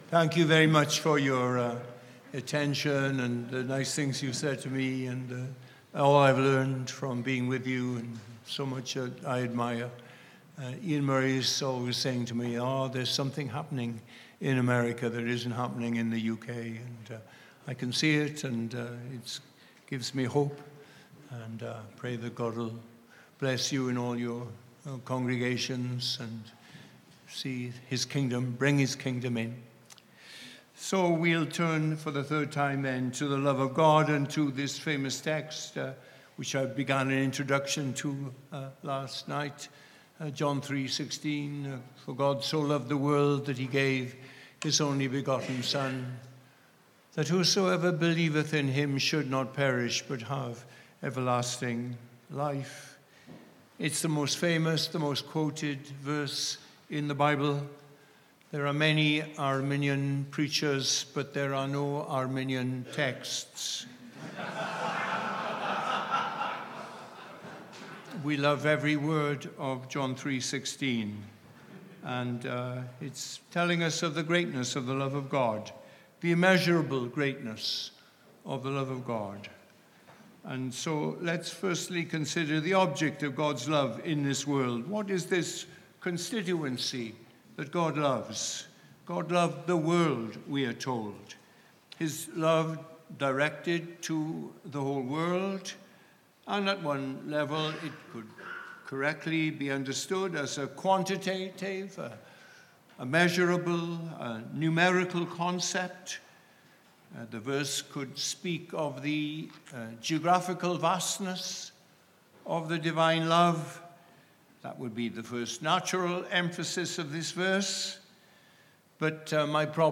Worship Service 3 – The Personal Nature of the Love